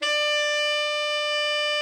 Index of /90_sSampleCDs/Giga Samples Collection/Sax/TENOR VEL-OB